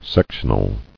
[sec·tion·al]